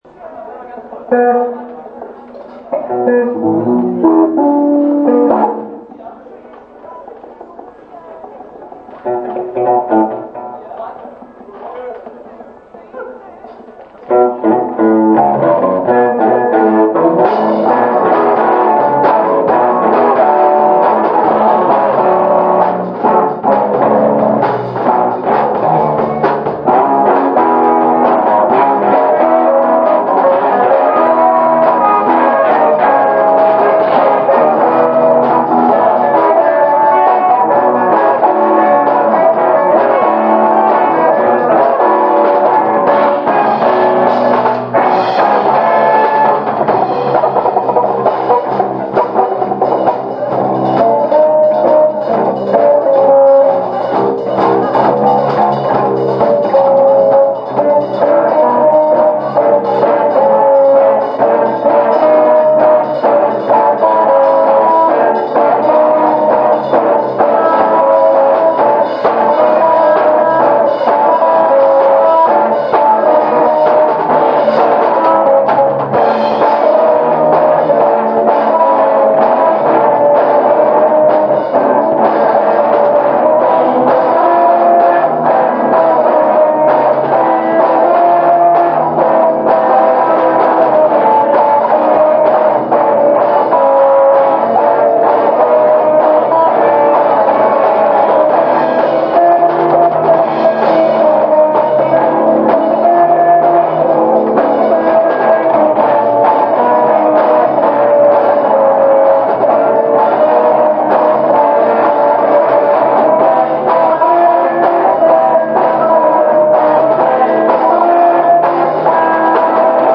Bass, Voc.
Gitarre, Voc.
Drums
Live im MP3 Format